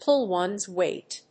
アクセントpúll one's (ówn) wéight